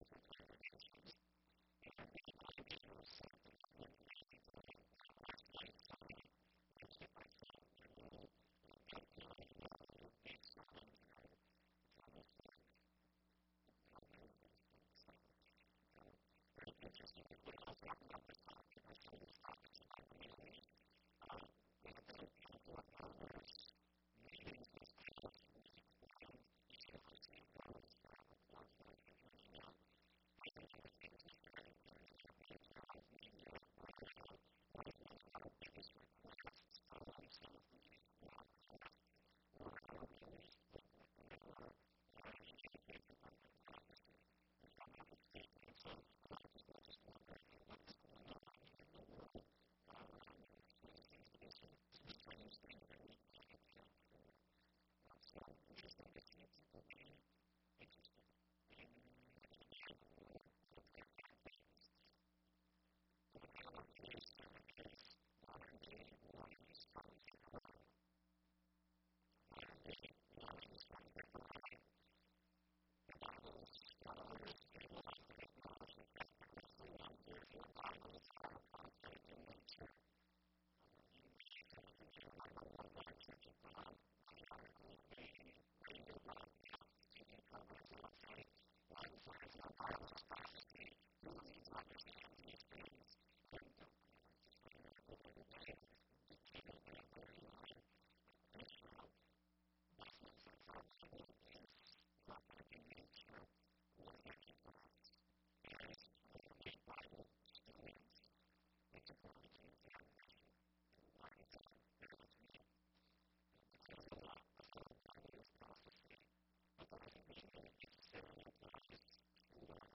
Given in Sioux Falls, SD Watertown, SD Bismarck, ND Fargo, ND